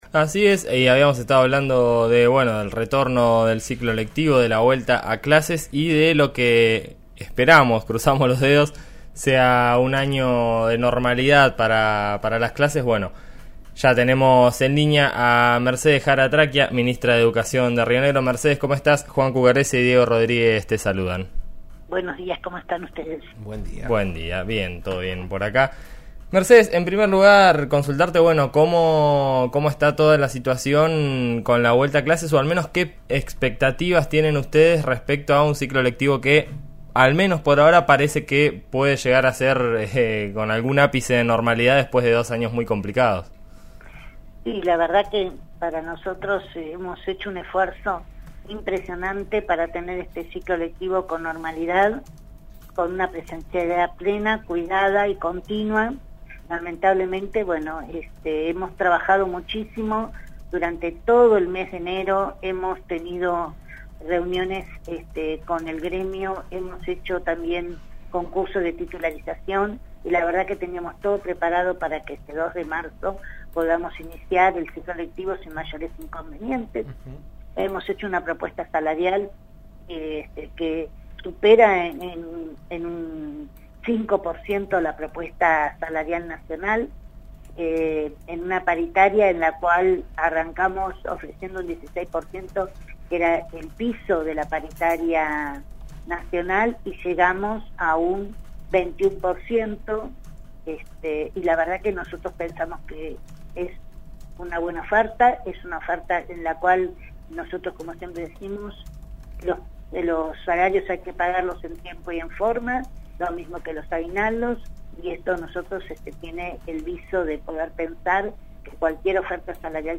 En eso estamos de RN Radio (89.3) dialogó con la Ministra de Educación de Río Negro, Mercedes Jara Tracchia, sobre el retorno a clases en la provincia.